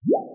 SFX_ItemPickUp_04_Reverb.wav